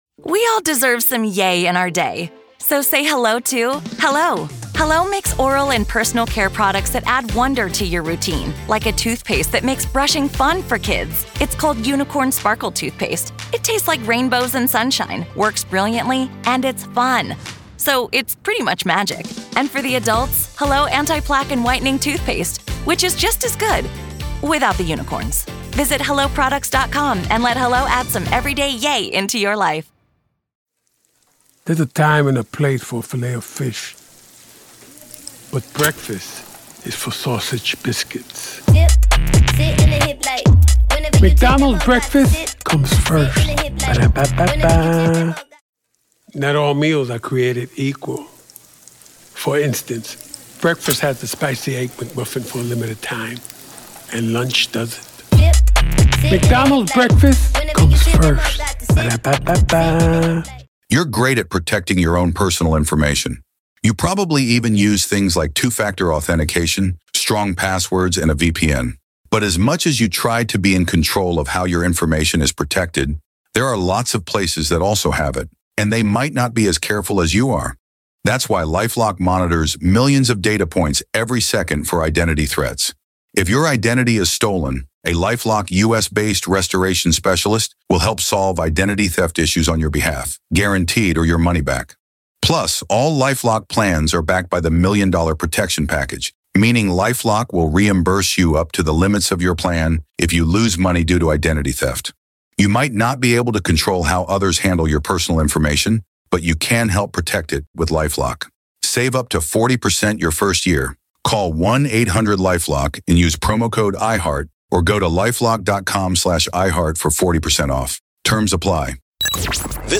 True Crime Today | Daily True Crime News & Interviews